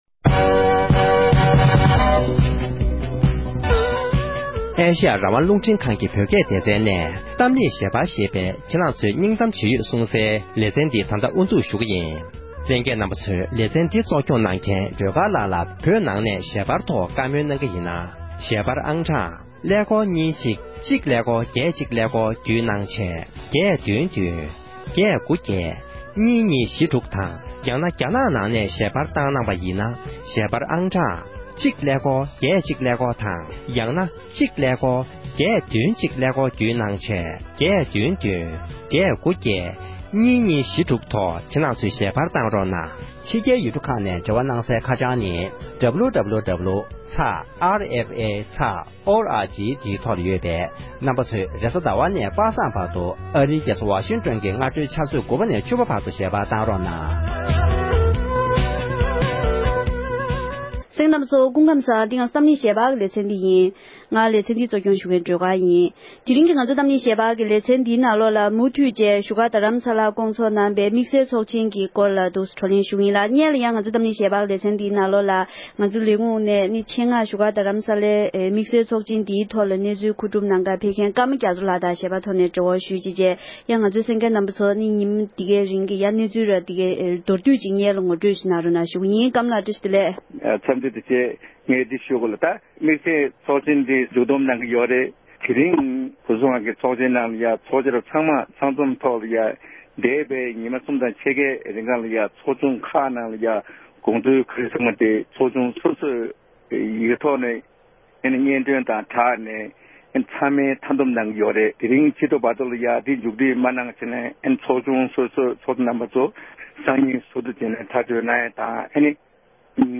བོད་ཕྱི་ནང་གཉིས་སུ་ཡོད་པའི་བོད་མི་རྣམས་ནས་དམིགས་བསལ་ཚོགས་འདུའི་ཐོག་བགྲོ་གླེང་གནང་བ།